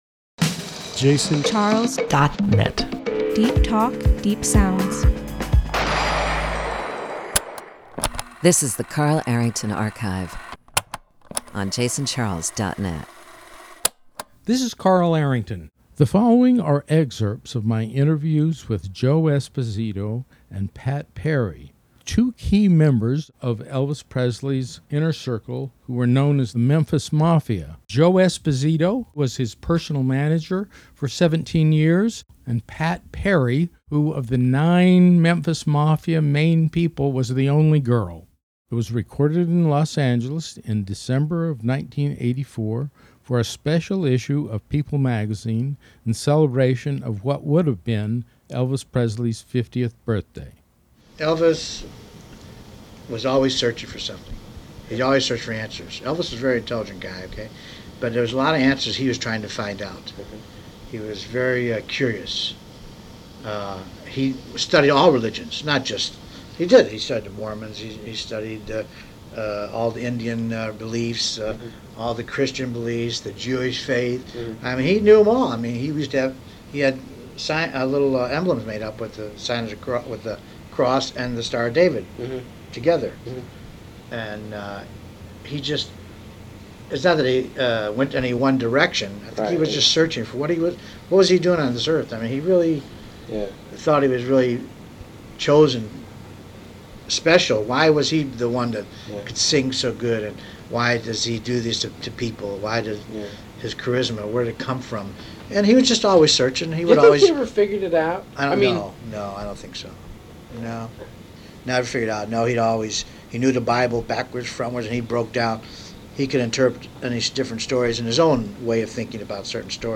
interviewed the key figures of the music icon’s inner circle in Los Angeles in December of 1984